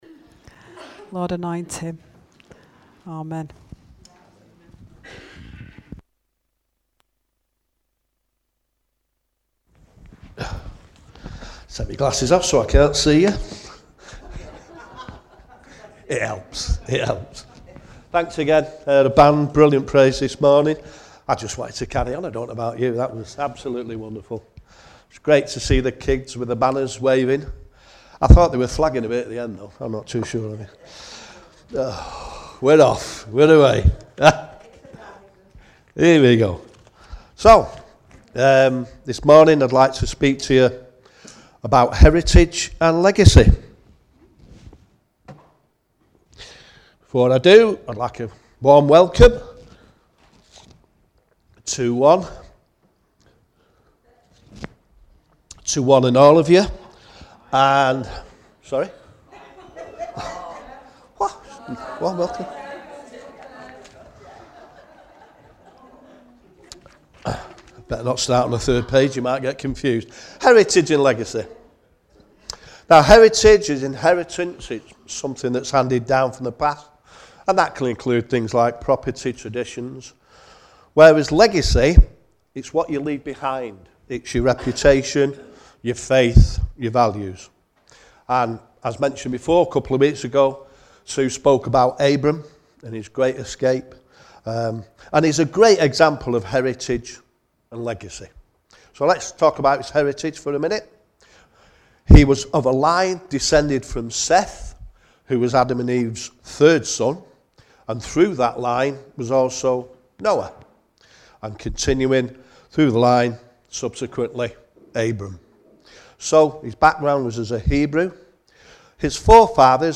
A talk about the early church.